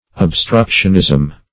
Search Result for " obstructionism" : Wordnet 3.0 NOUN (1) 1. deliberate interference ; The Collaborative International Dictionary of English v.0.48: Obstructionism \Ob*struc"tion*ism\, n. The act or the policy of obstructing progress.